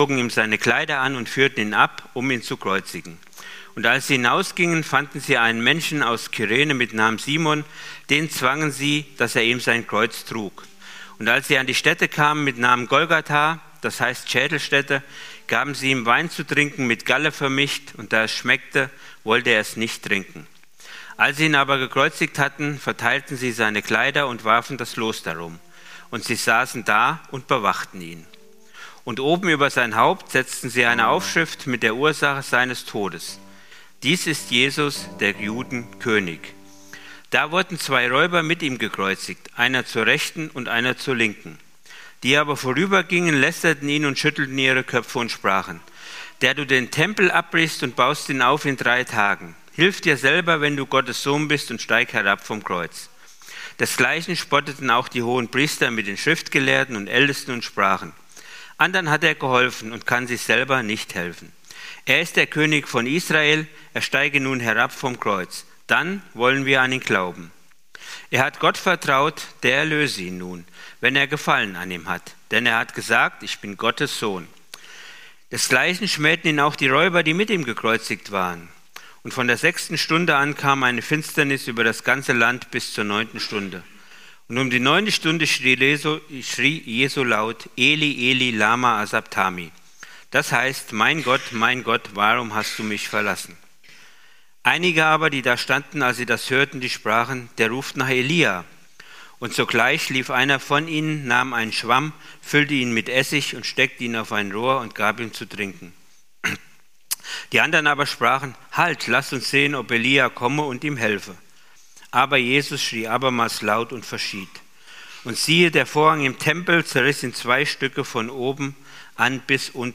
Predigten aus unserem Gottesdienst